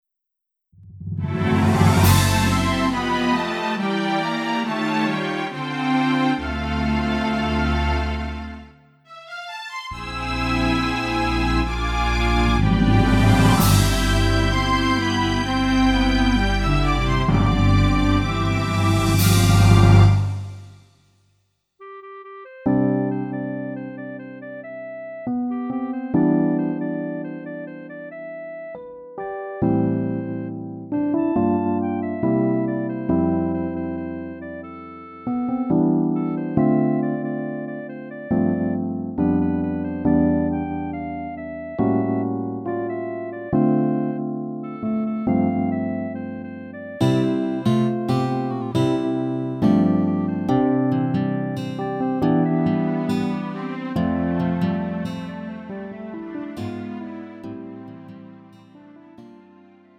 음정 원키 4:48
장르 가요 구분 Lite MR